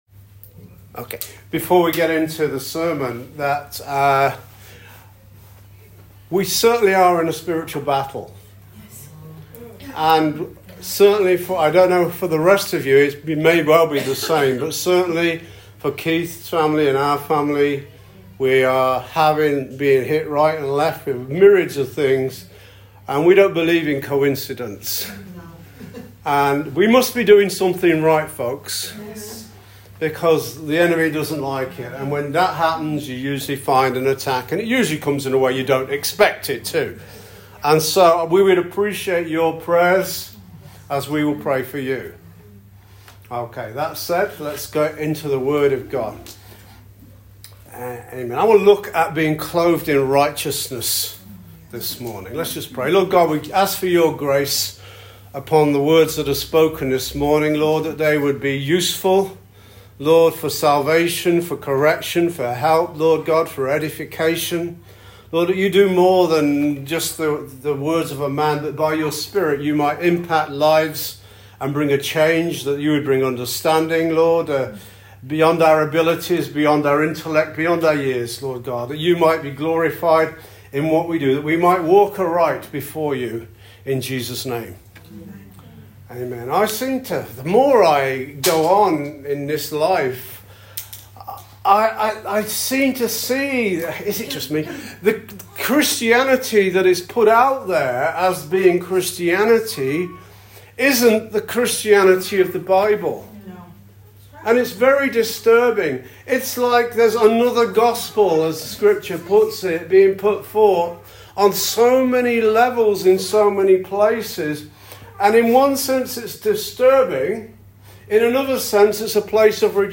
SERMON "CLOTHED IN RIGHTEOUSNESS”